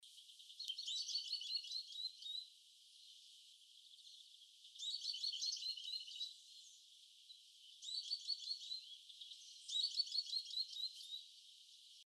Southern Beardless Tyrannulet (Camptostoma obsoletum)
Life Stage: Adult
Detailed location: Plaza Barrio Ciudad de Nieva
Condition: Wild
Certainty: Observed, Recorded vocal
PiojitoSilbon-1.mp3